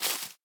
Minecraft Version Minecraft Version snapshot Latest Release | Latest Snapshot snapshot / assets / minecraft / sounds / block / cherry_leaves / step1.ogg Compare With Compare With Latest Release | Latest Snapshot
step1.ogg